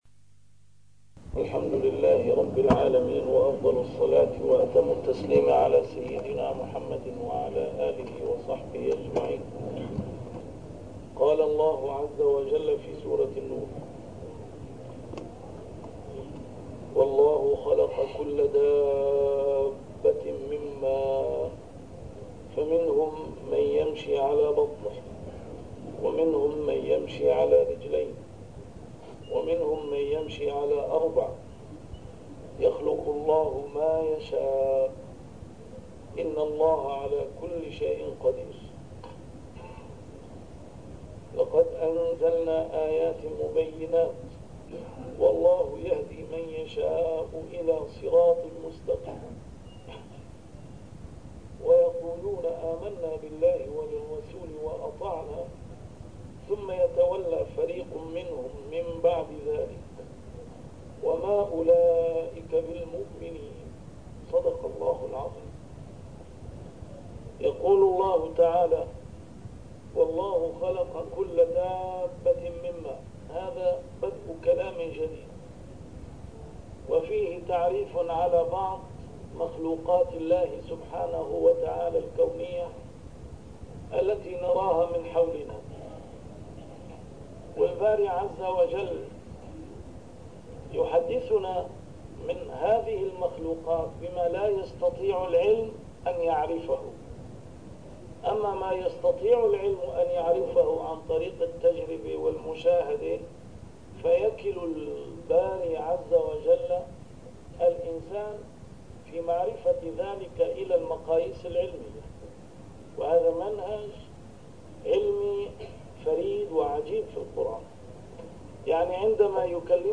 A MARTYR SCHOLAR: IMAM MUHAMMAD SAEED RAMADAN AL-BOUTI - الدروس العلمية - تفسير القرآن الكريم - تسجيل قديم - الدرس 193: النور 45-46